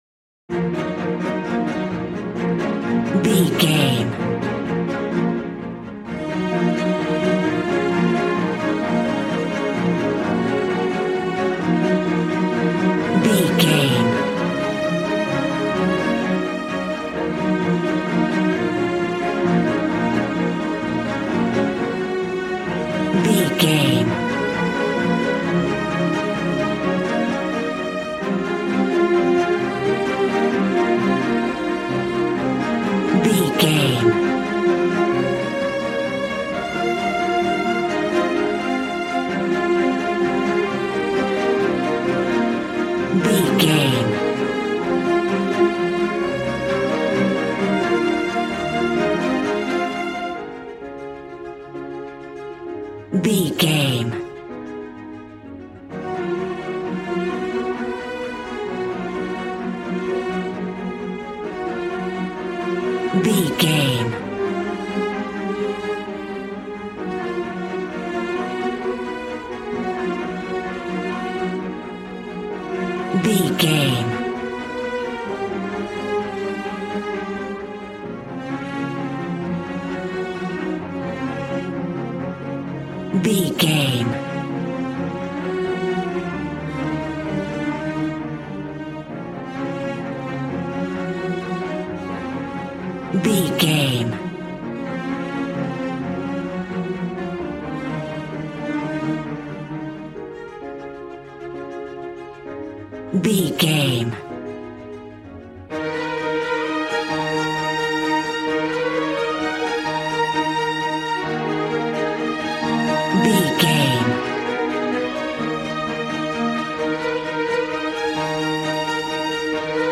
Regal and romantic, a classy piece of classical music.
Aeolian/Minor
B♭
regal
cello
violin
strings